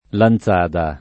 Lanzada [ lan Z# da ]